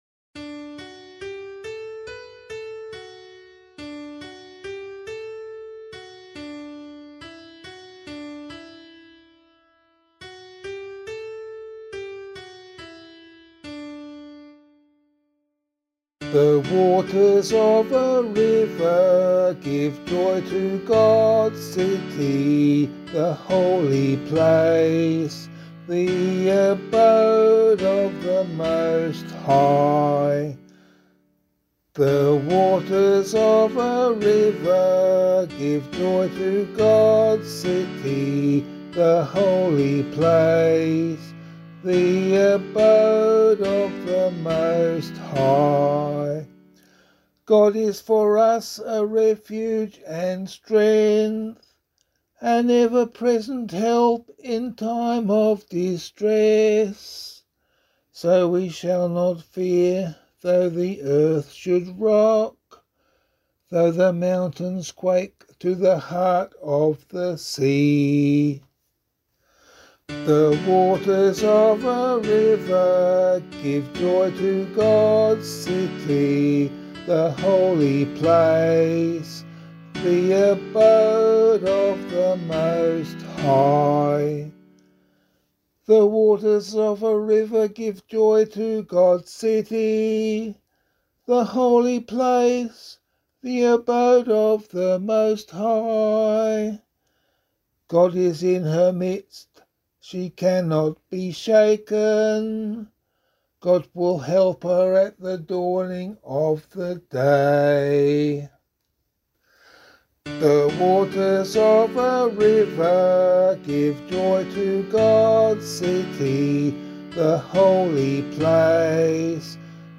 413 Lateran Basilica Psalm [APC - LiturgyShare + Meinrad 5] - vocal.mp3